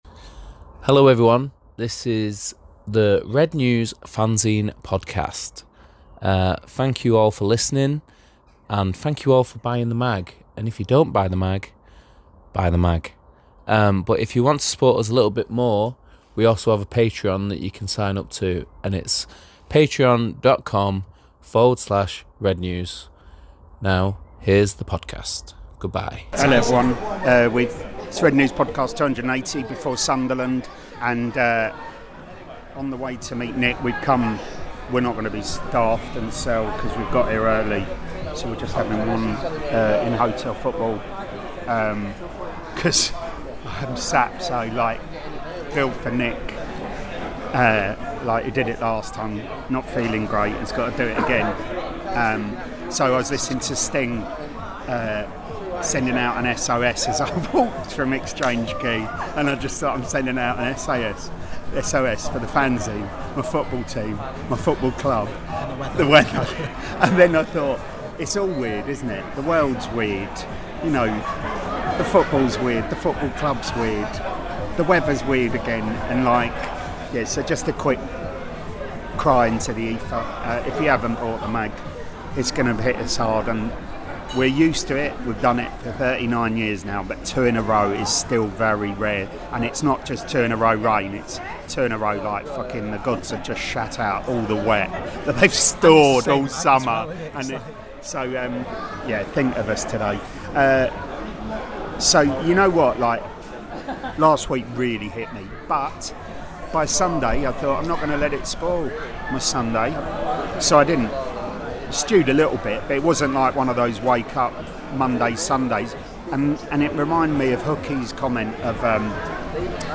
The independent, satirical Manchester United supporters' fanzine - for adults only, contains expletives, talks MUFC, or not at times, as we talk at Old Trafford and back in the pub.